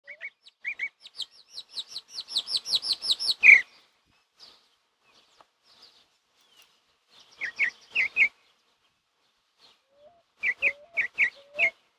Calandria Grande (Mimus saturninus)
Nombre en inglés: Chalk-browed Mockingbird
Localidad o área protegida: Valle Inferior del Río Chubut (VIRCH)
Condición: Silvestre
Certeza: Observada, Vocalización Grabada
Calandria-Grande.mp3